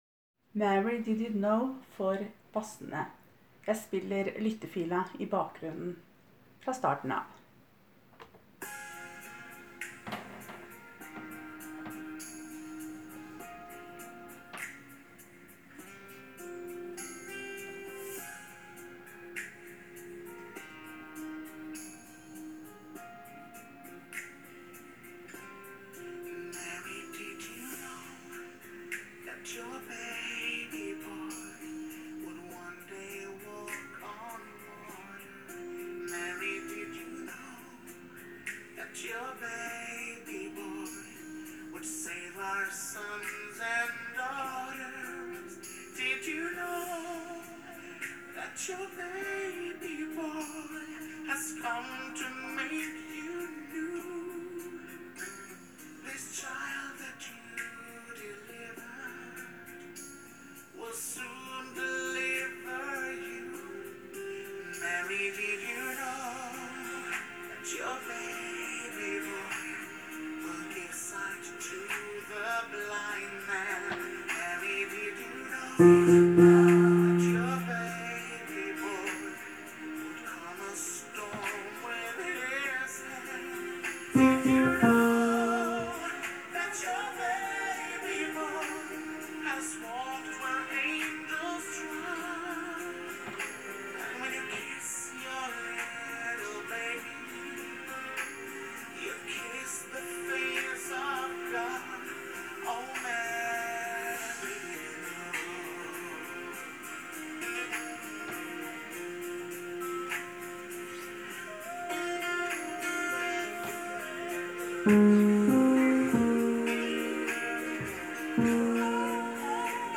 Jul 2017 Bass (begge konserter)
Mary did you know med lyttefil i bakgrunnen:
Mary-did-you-know-Bassene-Lyttefila-i-bakgrunnen.m4a